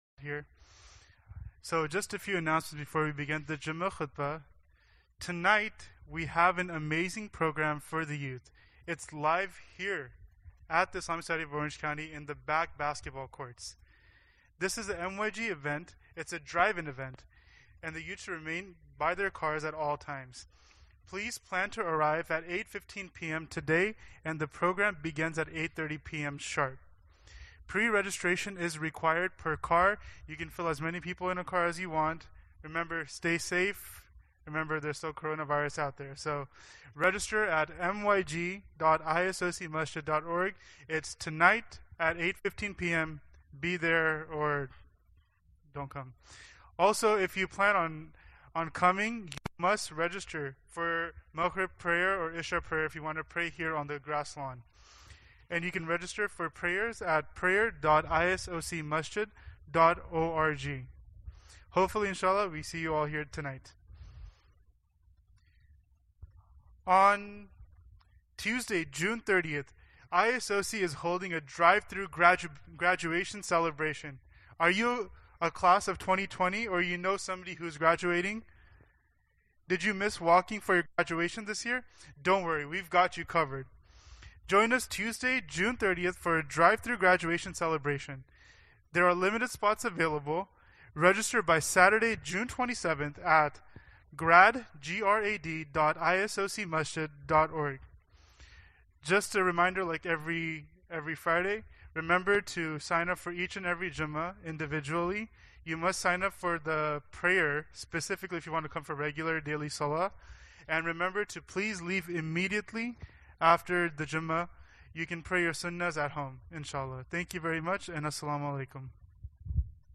Friday Khutbah - "Liberty and Freedom"